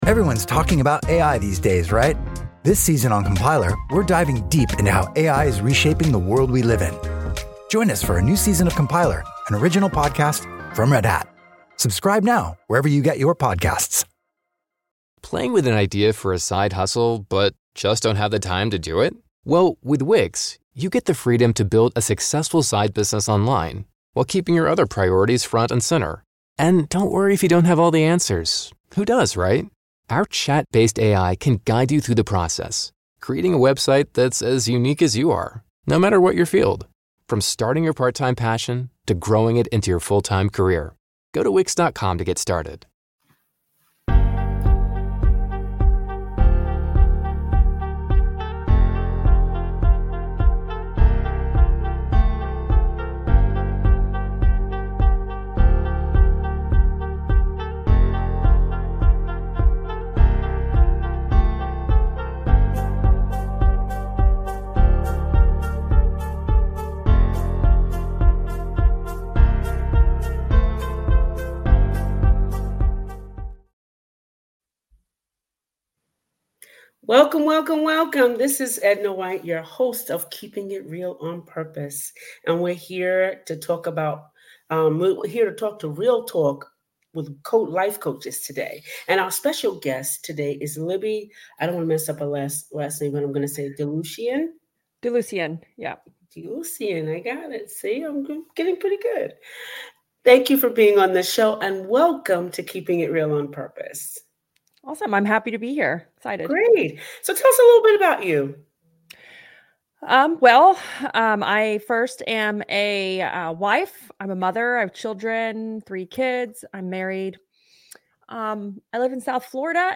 Join us for an inspiring conversation